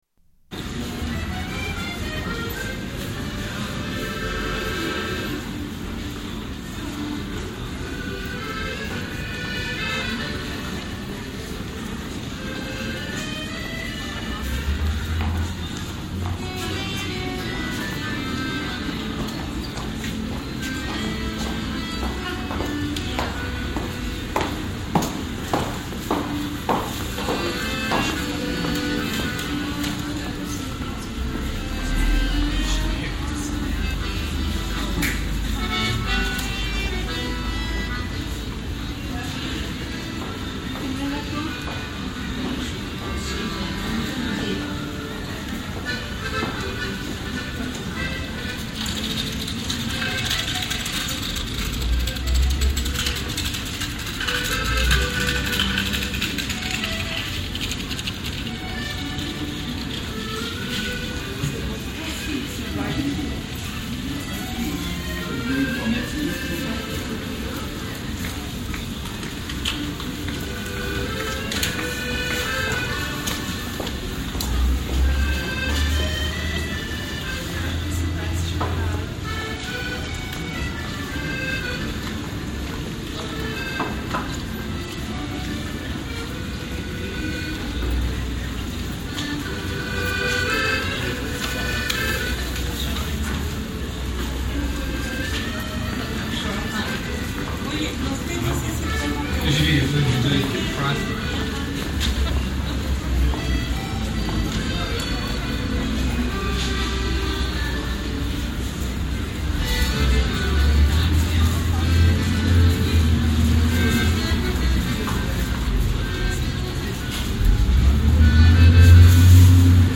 Warm spring day
Tags: Travel Sounds of Austria Austria Holidays Vienna